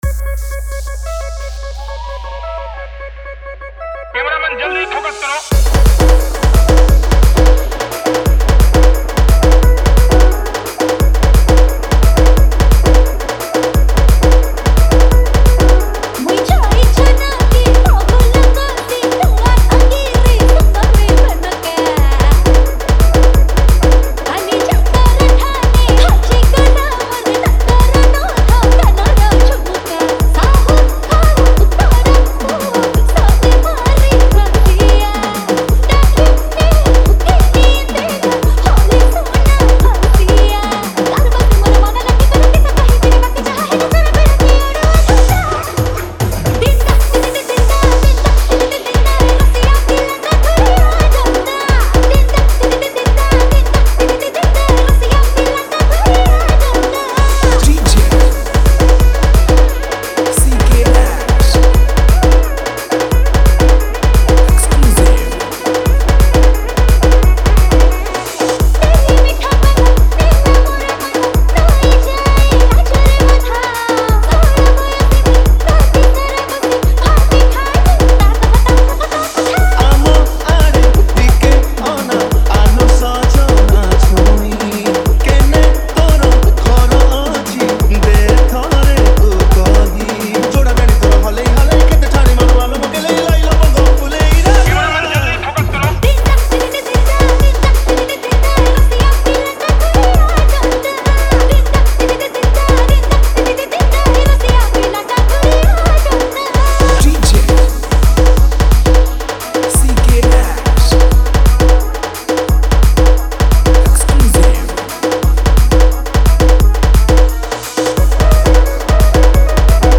Single Dj Song Collection 2023 Songs Download